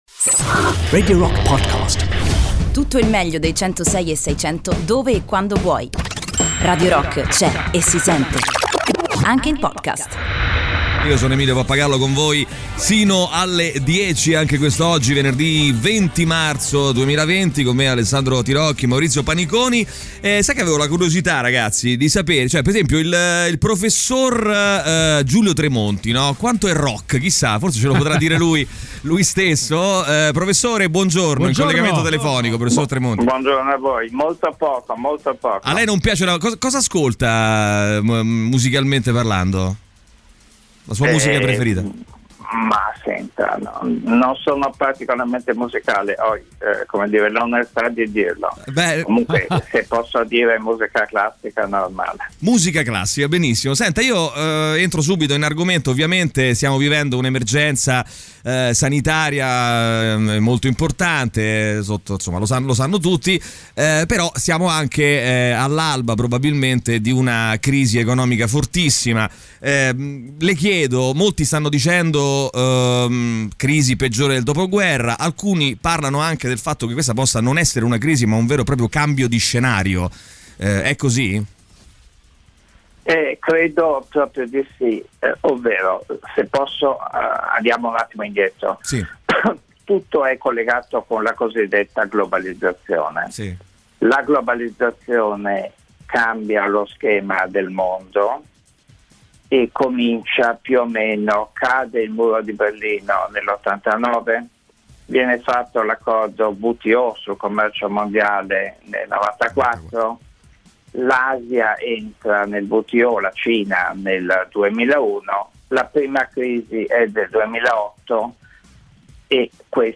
Intervista: “Giulio Tremonti" (20-03-20)